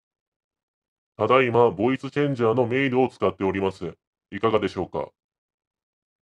AmpliGame SC3には「Male」「Female」「Robot」「Monster」「Baby」「Elder」のボイスチェンジ機能があるので、試しに「Male」「Female」を使って録音してみました。
「Male」の音声
ボイスチェンジは普通にクオリティ高いと思いました。
sc3-male.mp3